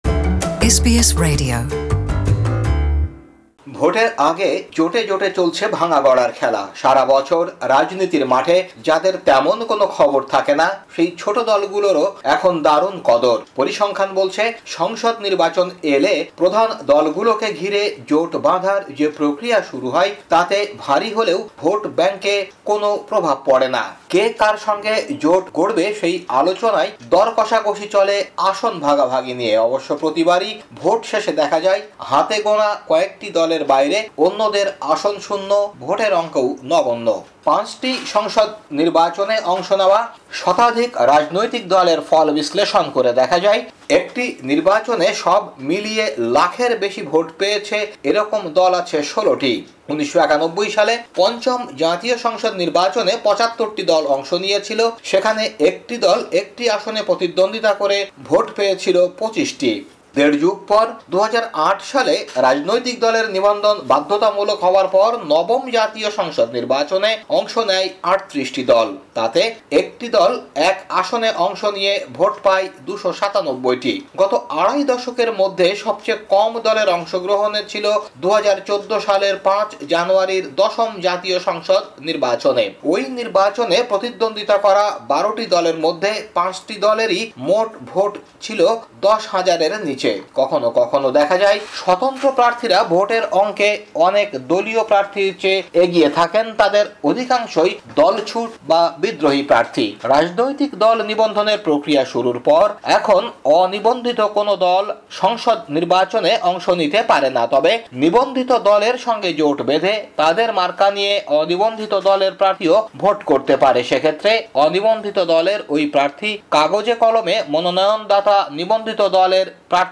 বাংলাদেশী সংবাদ বিশ্লেষণ: ২৯ অক্টোবর ২০১৮